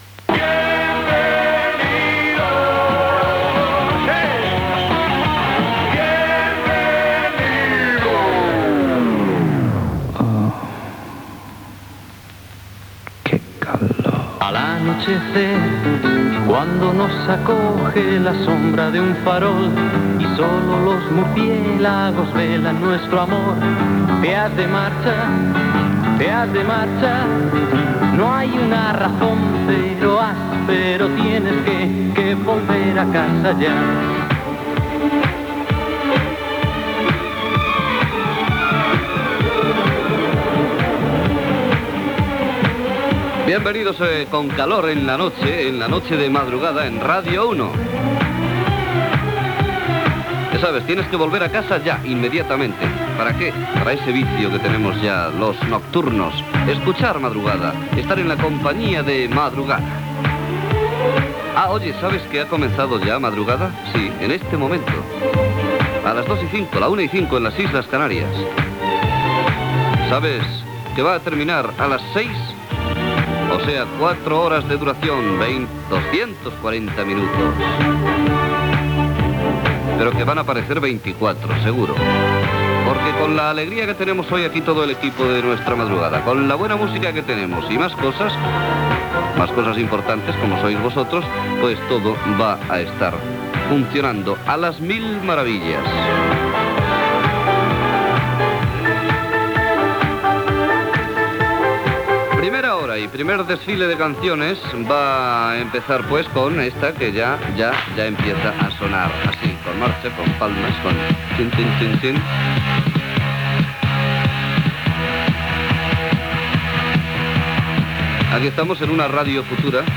Identificació del programa, presentació i tema musical
Musical